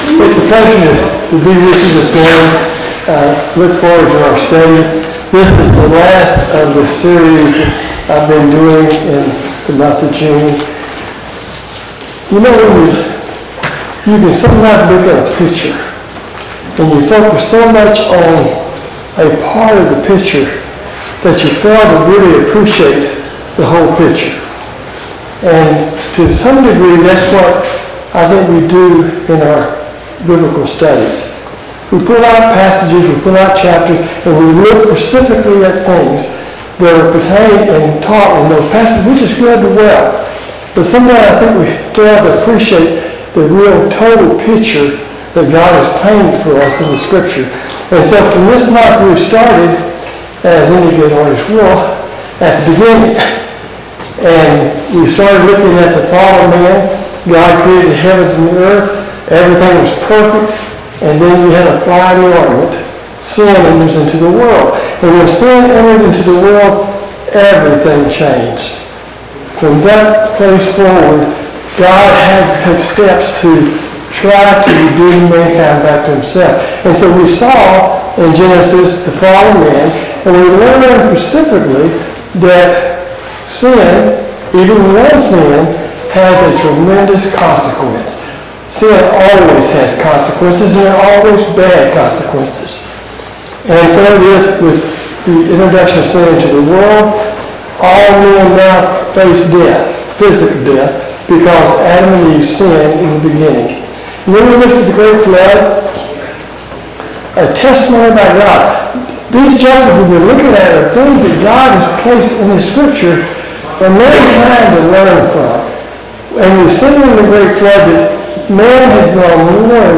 Sunday Morning Bible Class